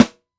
• Original Snare One Shot B Key 121.wav
Royality free steel snare drum tuned to the B note. Loudest frequency: 916Hz
original-snare-one-shot-b-key-121-v41.wav